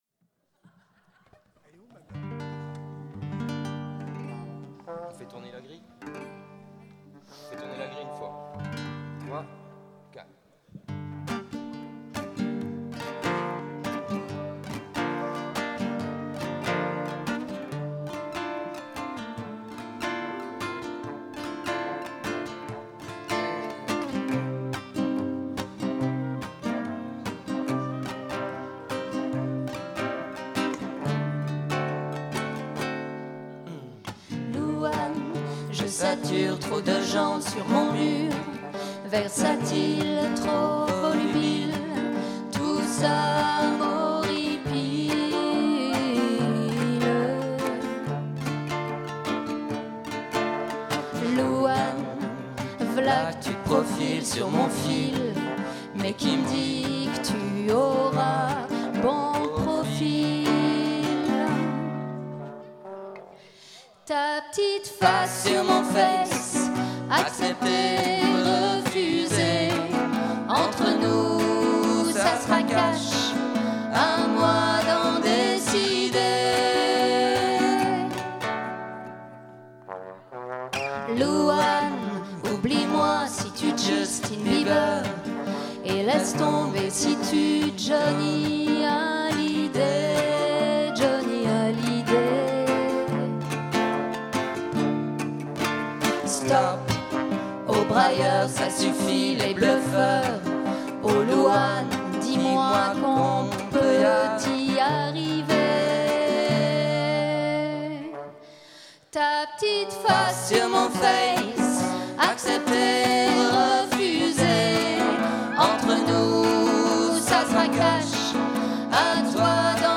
lectures audio